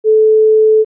LongBeep.aif